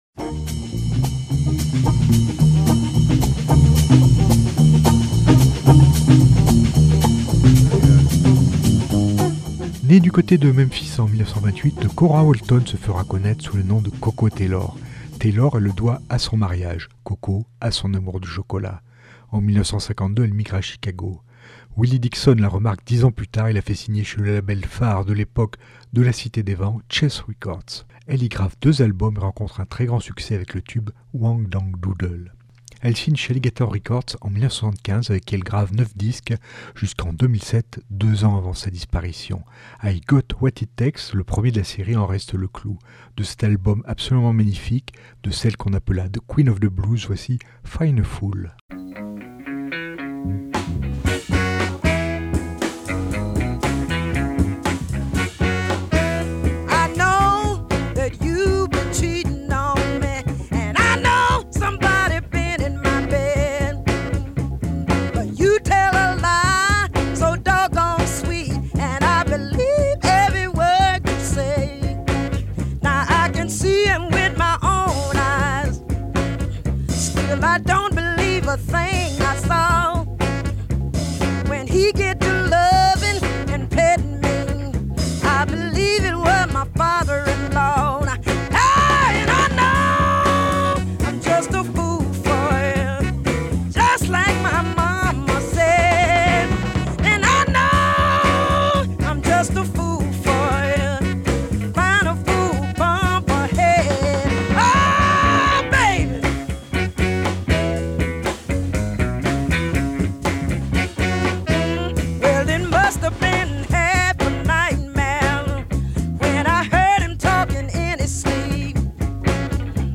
All Blues n°888 - Le Collectif des Radios Blues francophones (CRB)